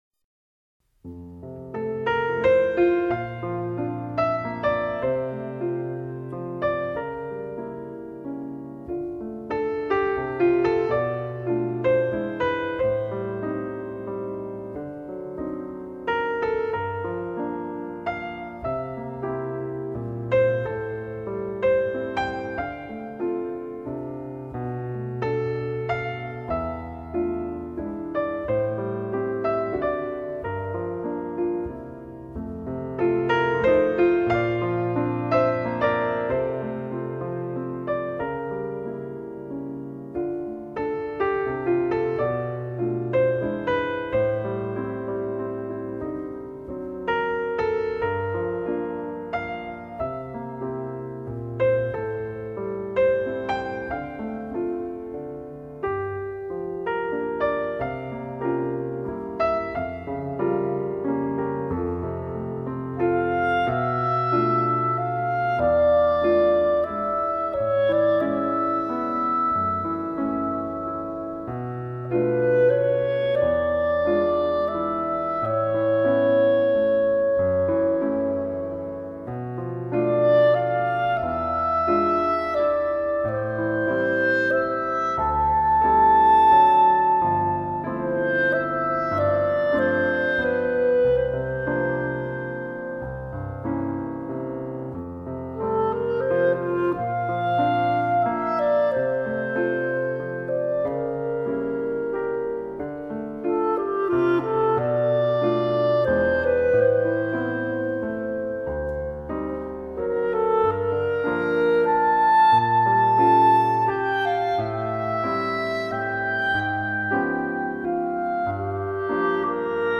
简单的音符在钢琴中化为点点滴滴的流水轻轻敲打在我的心头。
琴声在静谧中激起涟漪，轻柔的滑过指间，漫过心间，感觉如细雨般朦胧和清凉。
微妙的着色，包括小提琴，大提琴和单簧管混合着，与钢琴合奏着一个纯净而绿色的背景世界。